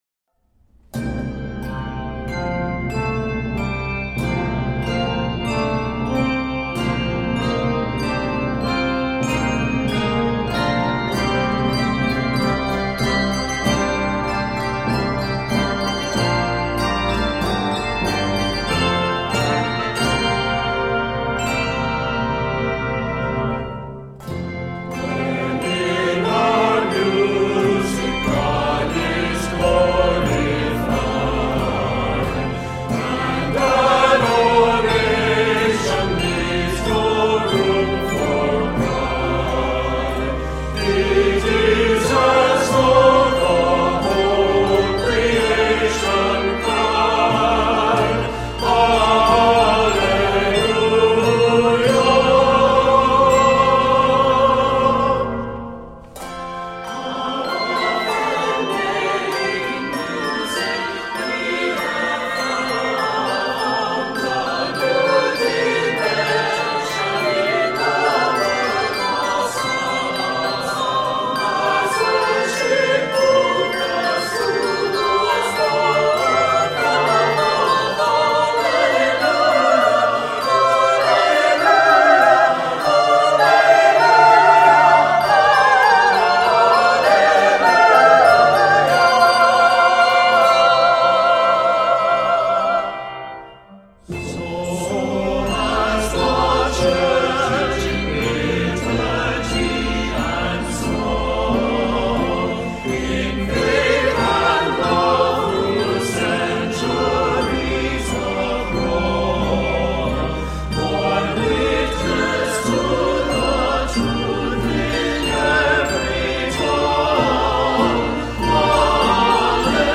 handbells
It is 66 measures in length and is scored in F Major.